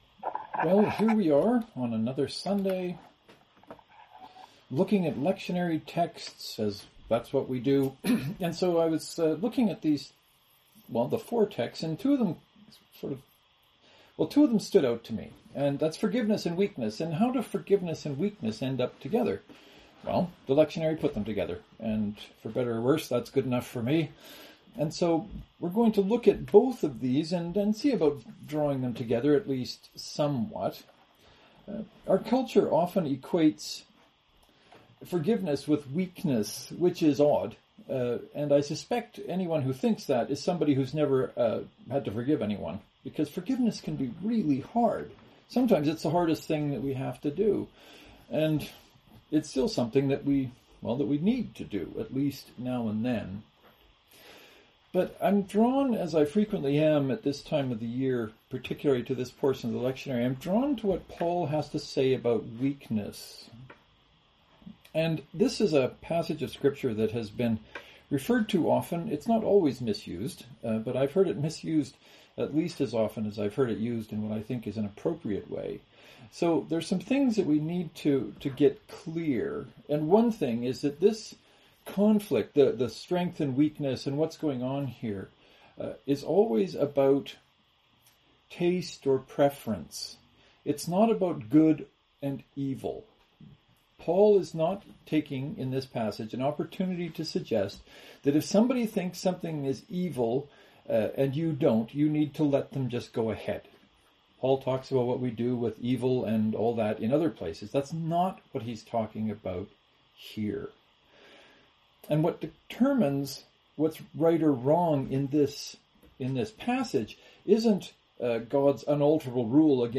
“Forgiveness and weakness” Knox and St. Mark’s Presbyterian joint service (to download, right click and select “Save Link As .